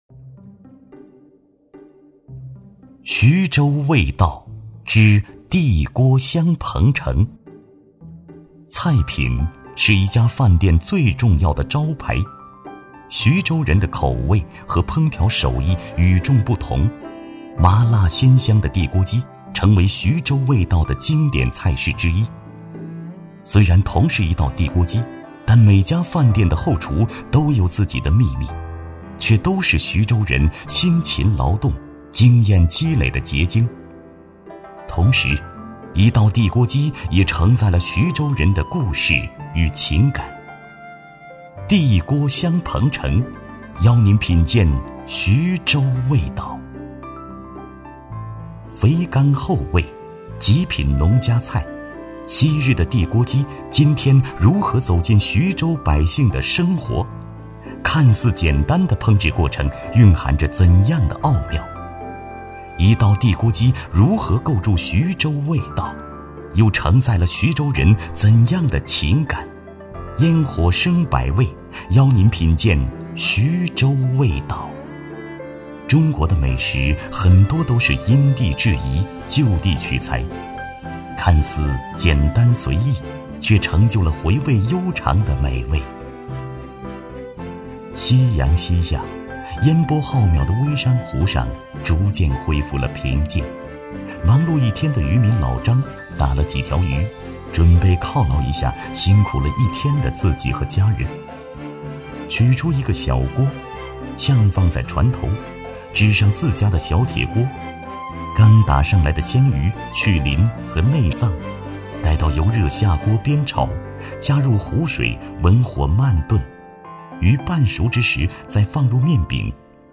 特点：大气浑厚 稳重磁性 激情力度 成熟厚重
风格:浑厚配音
A47男-徐州舌尖美食.mp3